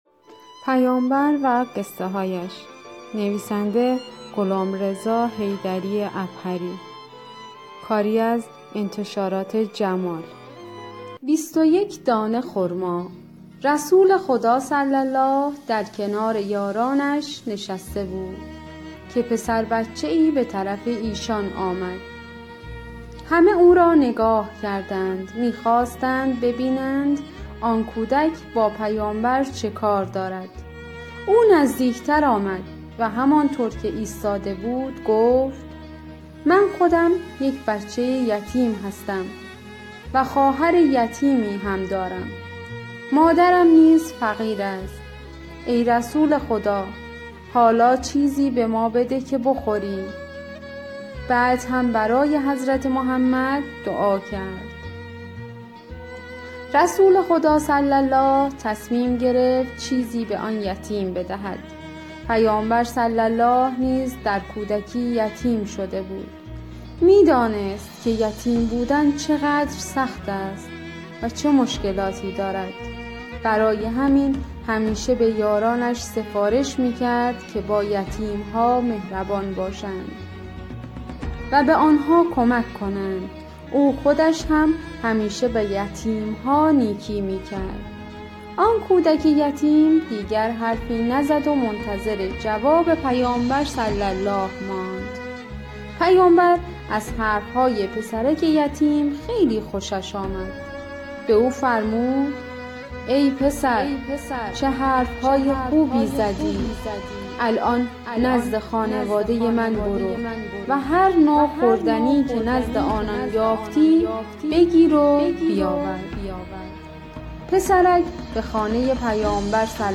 قصه-گویی-پیامبر.mp3